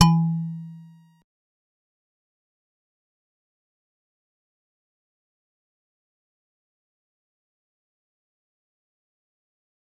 G_Musicbox-E3-pp.wav